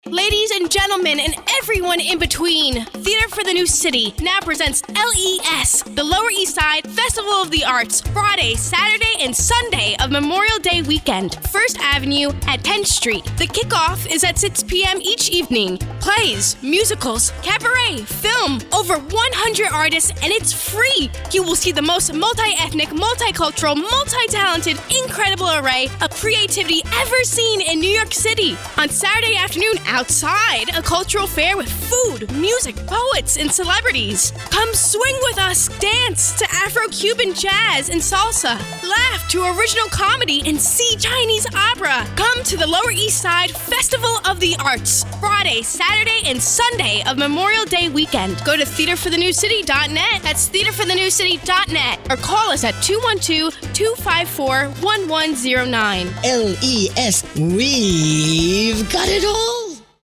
Listen to our LES promo on 1010 WINS!